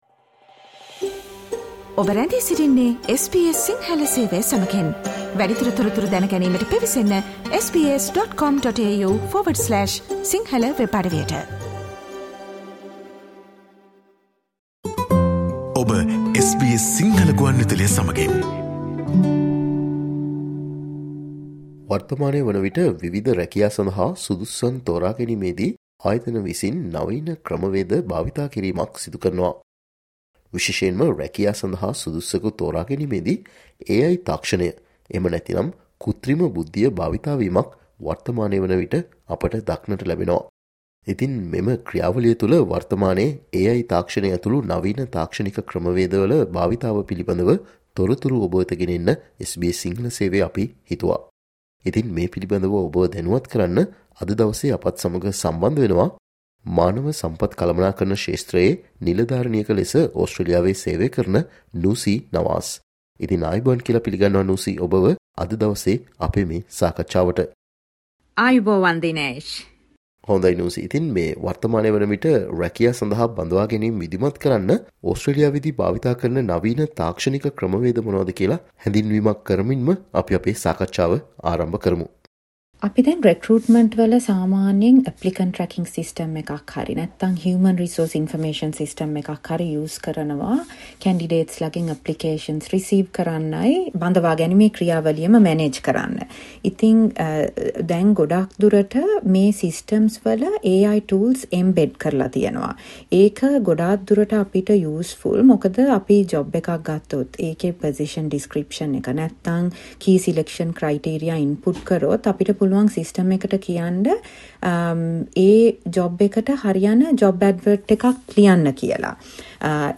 HR Consultant